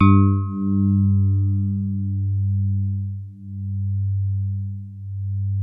TINE HARD G1.wav